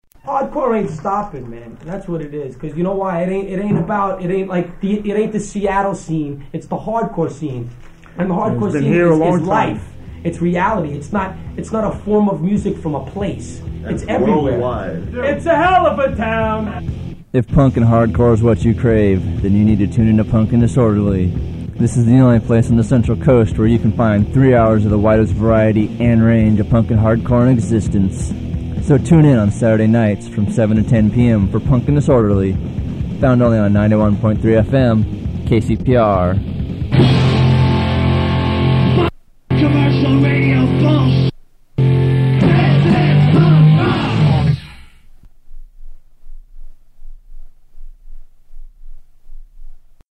Punk and Disorderly [advertisement]
Form of original Audiocassette